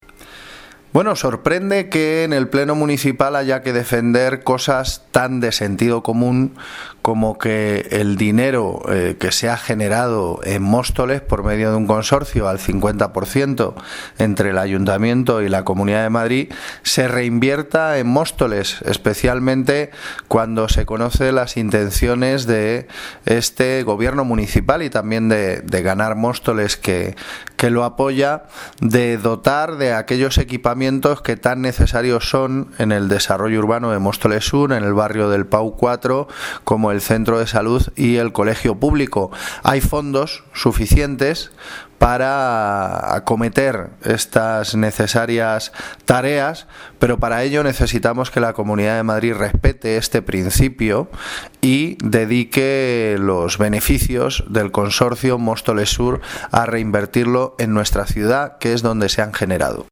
Audio - Gabriel Ortega (Portavoz de Ganar Móstoles) Aprobación moción beneficios Móstoles sur